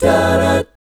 1-F#MI7   -L.wav